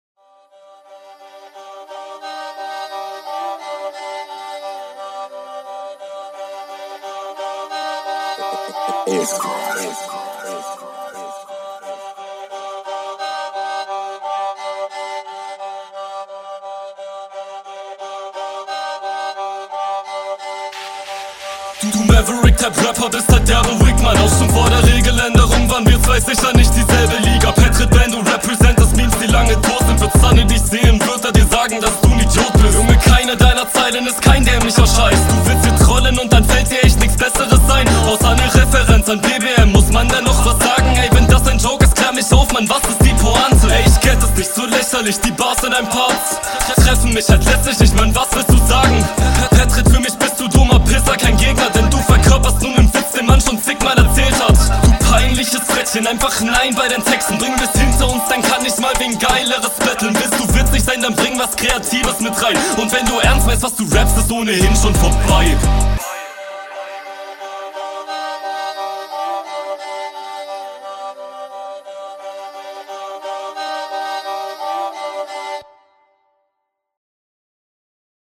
Audio ist stark.
Beat gefällt mir leider gar nicht aber nicht so wichtig.